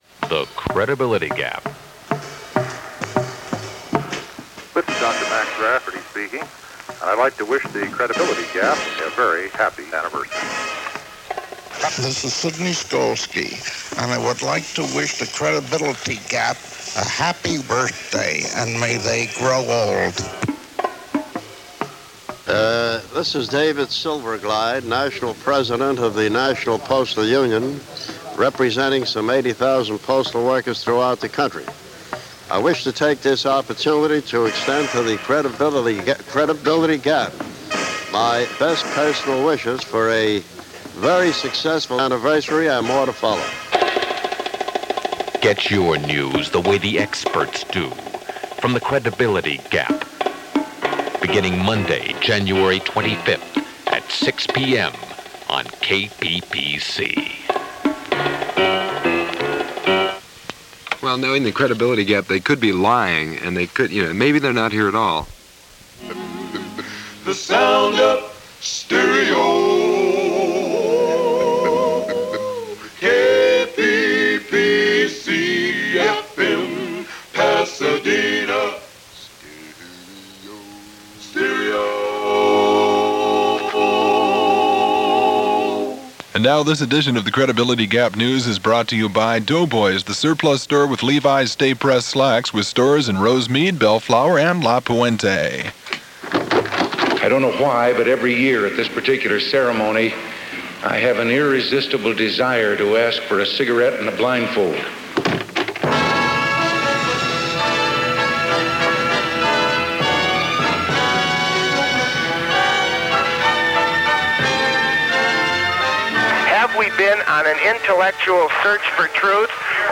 A blend of surreal situational humor, mixed with absurd humor based on headlines of the day, The Credibility Gap initially started life as a daily spoof of news on Top-40 AM station KRLA in Pasadena, around the same time Radio Free Oz (Firesign Theatre) took over weekend nights at the station.